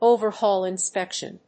overhaul+inspection.mp3